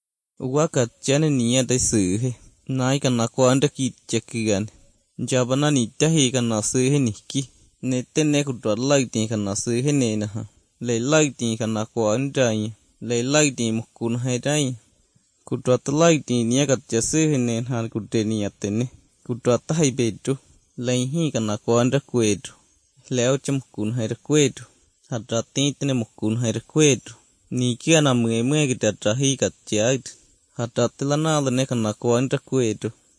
Retroflexes make me think of South Asia and Australia. Palatals make me think of Southeast Asia as well.
The nasal vowels, possibly non-modal phonation, and tone also point to those last three regions.